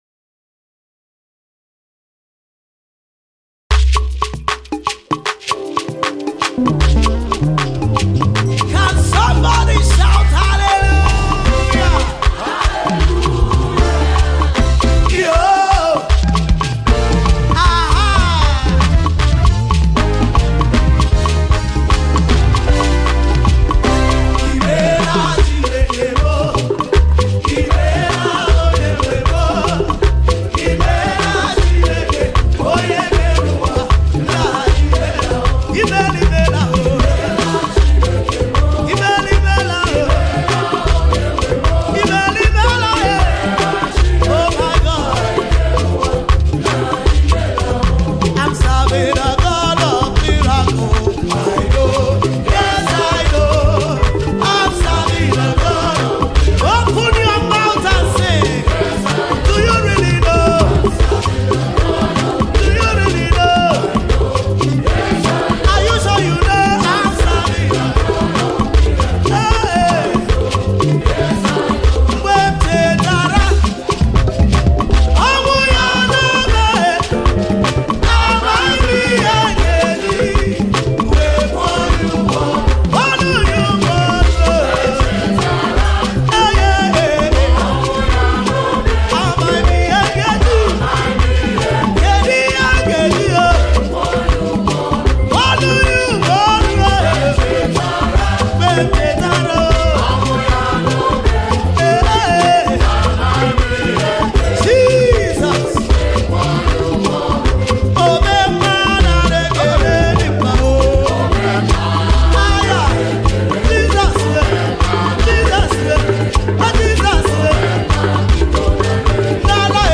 Starting up the new month with an exuberant praise comes
Enjoy the beautiful collection of praise songs.